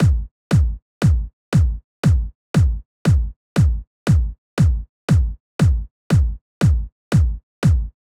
12 Kick.wav